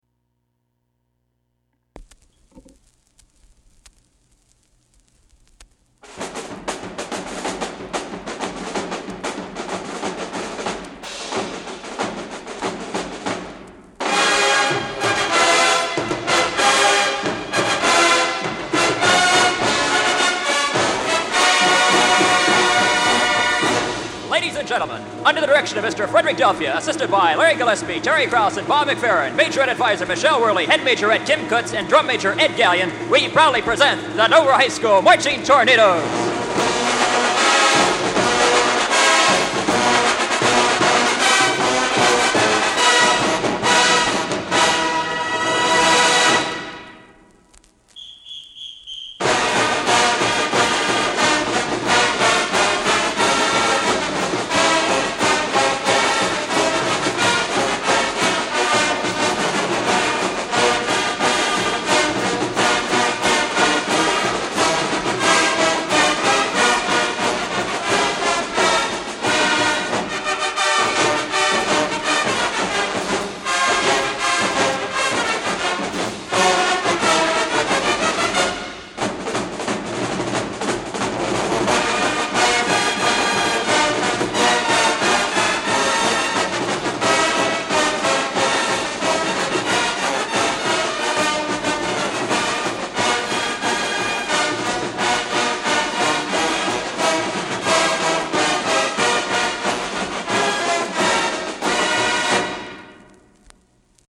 Marching Tornados Band
1973 Marching Tornados Band LP Recording